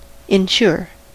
Ääntäminen
IPA: /fɛɐ̯ˈzɪçɐn/